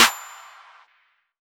Snare (8).wav